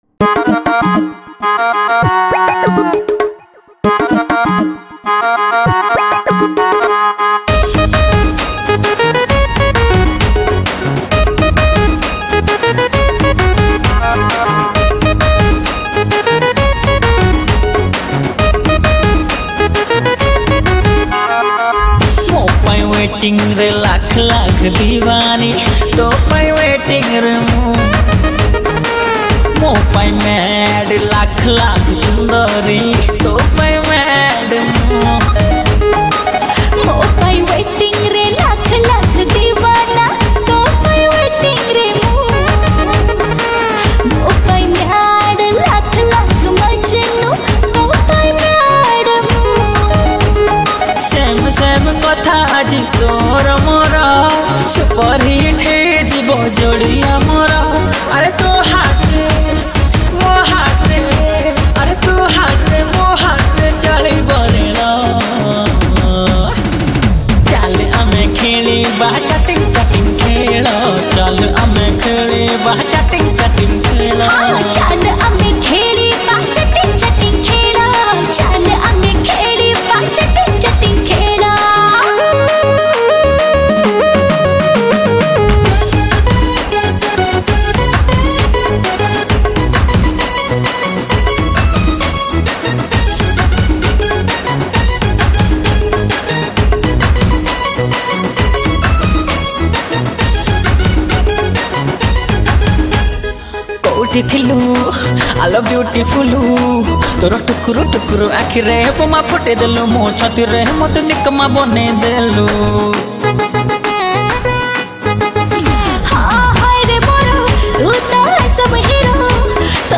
Durms
KeyBoard